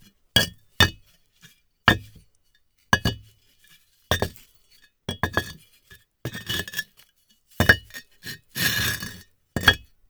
CONSTRUCTION_Bricks_Slow_loop_mono.wav